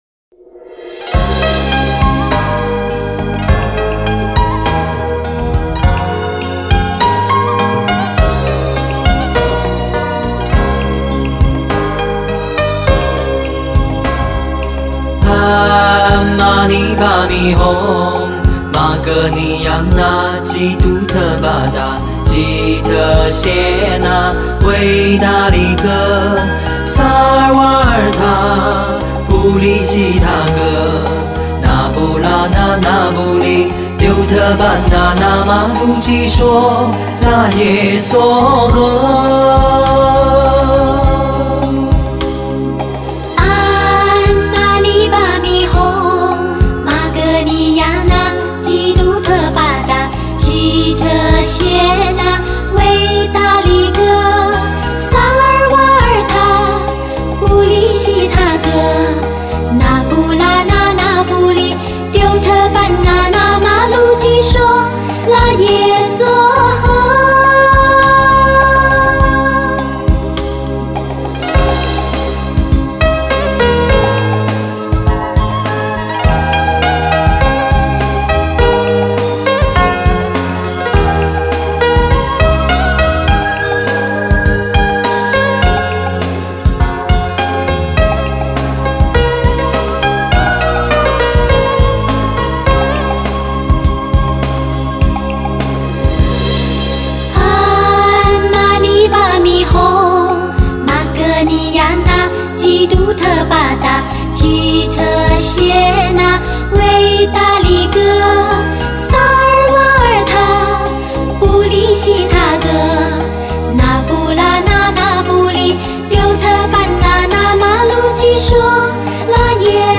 [27/10/2010]动听的男女汉音合唱：《观音灵感真言》